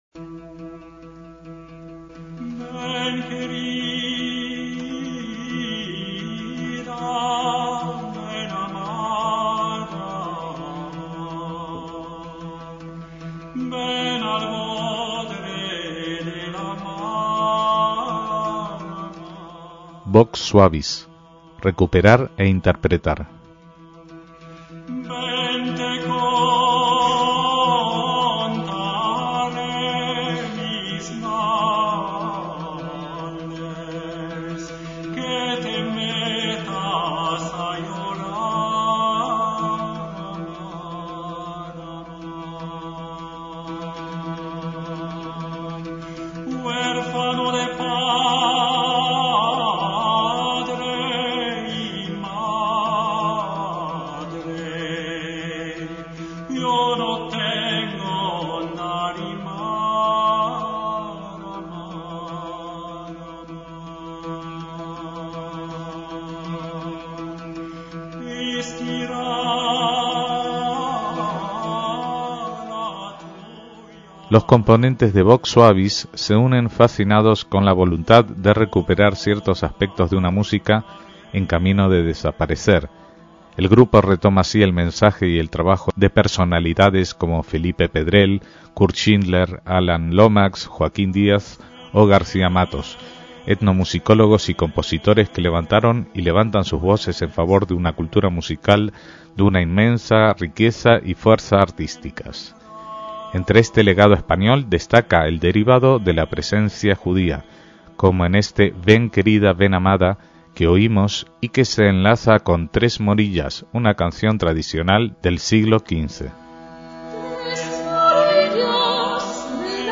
MÚSICA SEFARDÍ
voz y percusiones
voz y laúd árabe